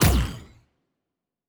Weapon 03 Shoot 2.wav